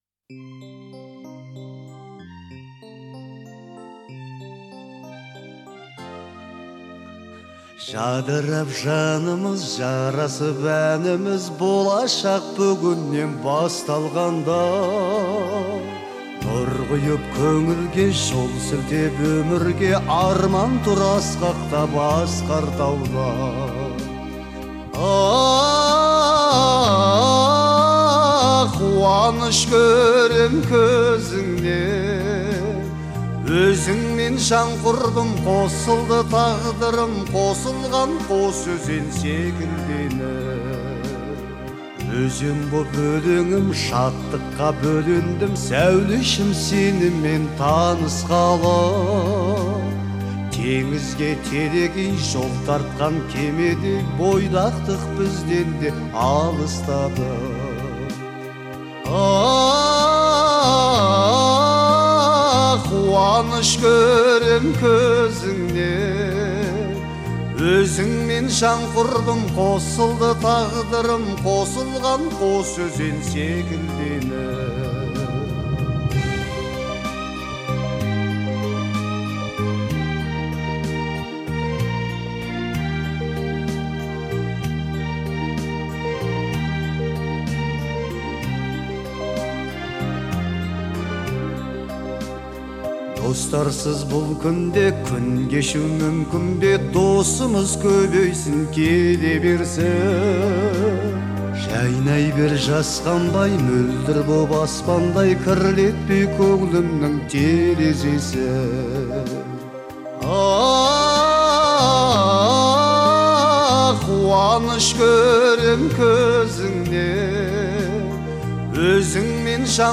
это нежная и мелодичная композиция в жанре вальс